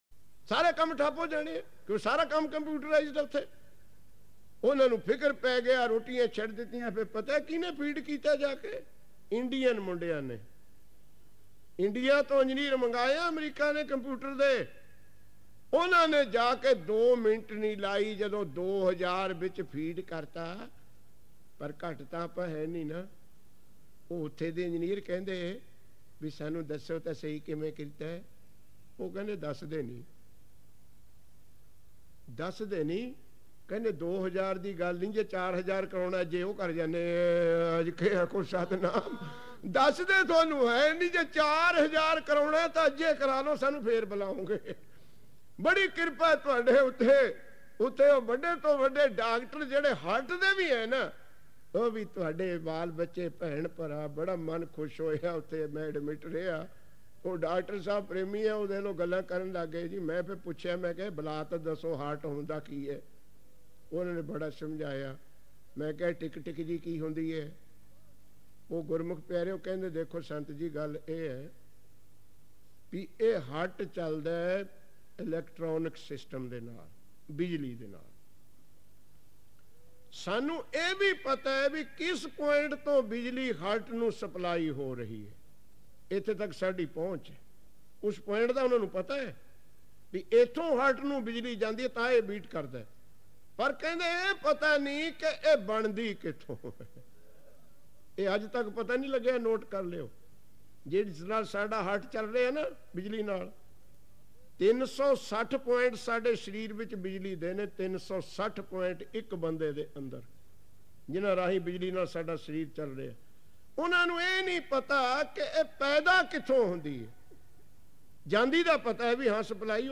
Kirtan with katha
Album:Hukam Rajai Chalna Genre: Gurmat Vichar